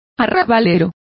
Complete with pronunciation of the translation of suburban.